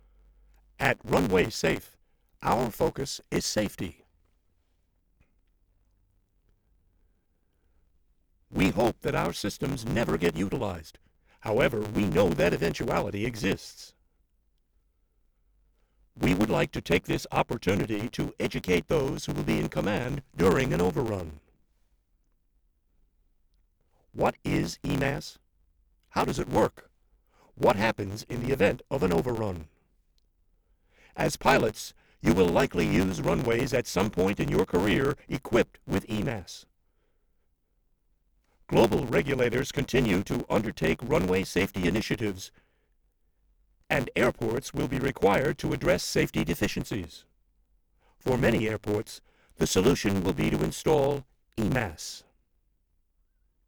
Distortion
Presonus Audiobox directly wired to the computer.